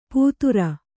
♪ pūture